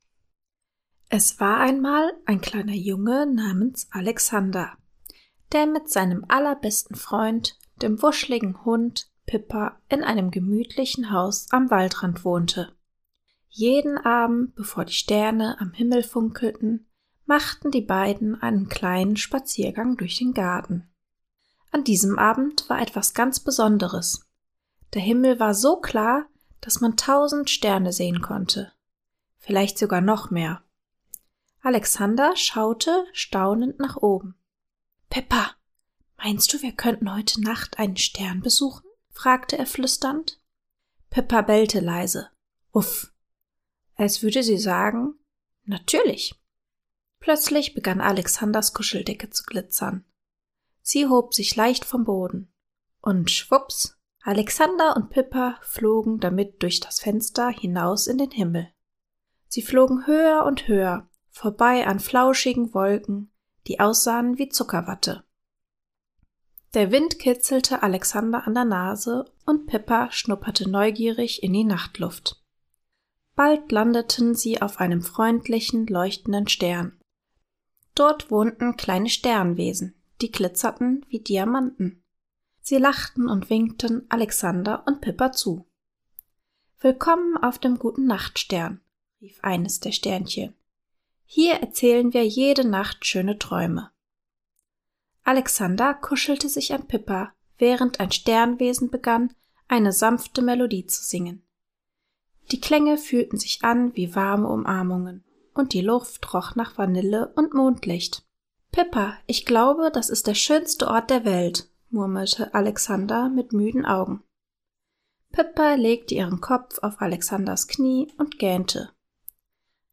Eine beruhigende Einschlafgeschichte
Mit sanften Tönen und viel Herz erzählt – für